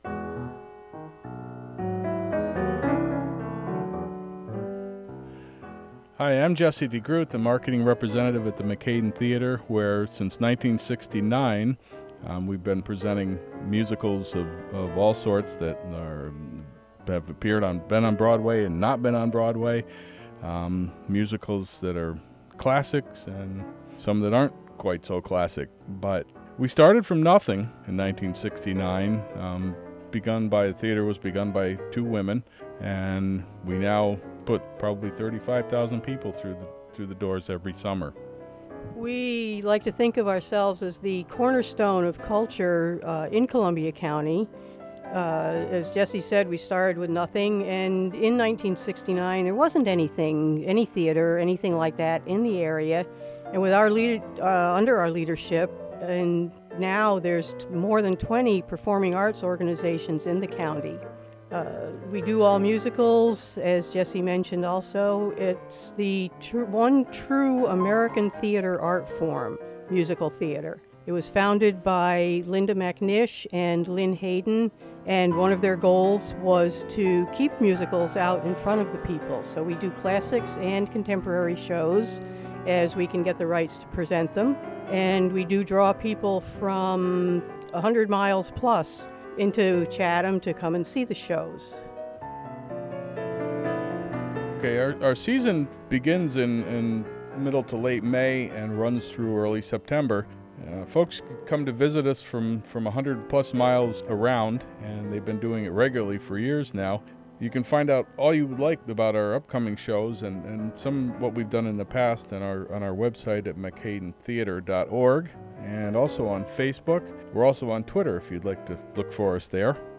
The interview was recorded at WGXC's first PSA day.